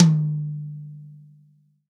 • Hi Tom Sample D Key 01.wav
Royality free tom sound tuned to the D note. Loudest frequency: 1047Hz
hi-tom-sample-d-key-01-pnO.wav